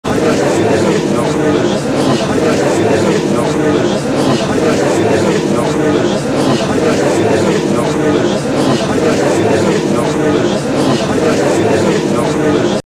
sfx_crowd_murmur.mp3